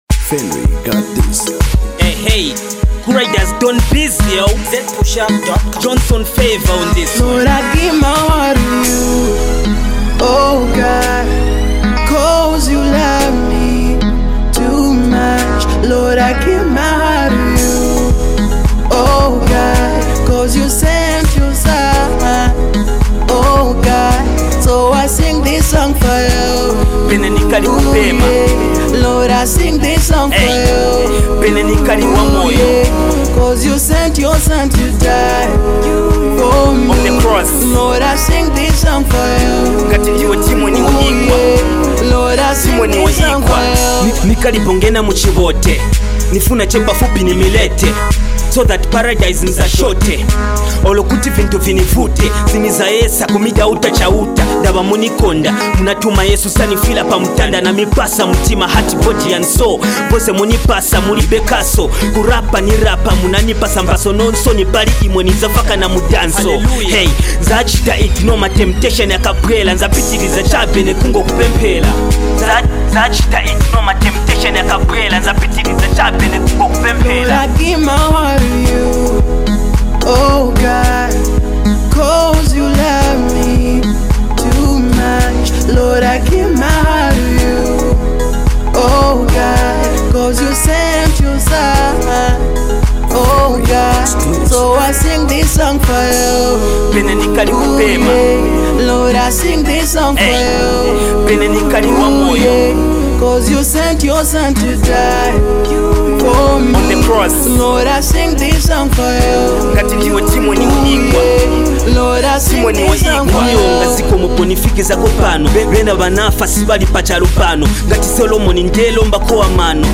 gospel jam
a well composed chorus